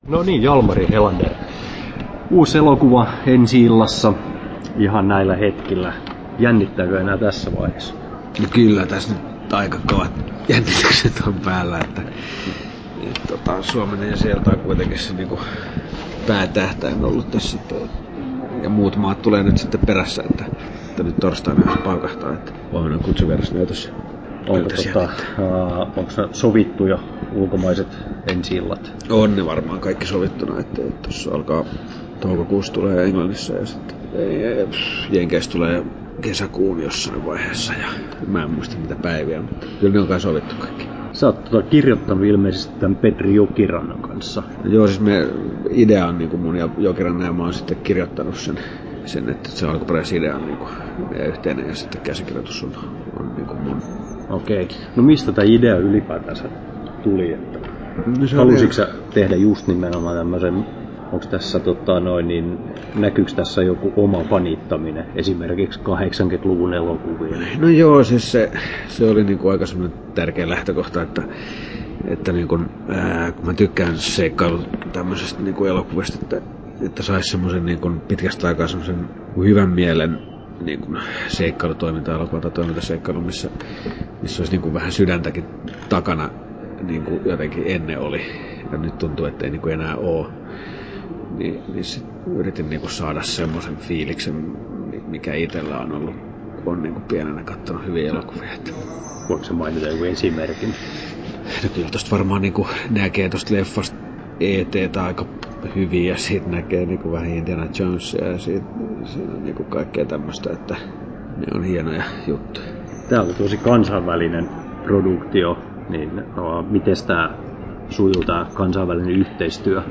Haastattelussa Jalmari Helander Kesto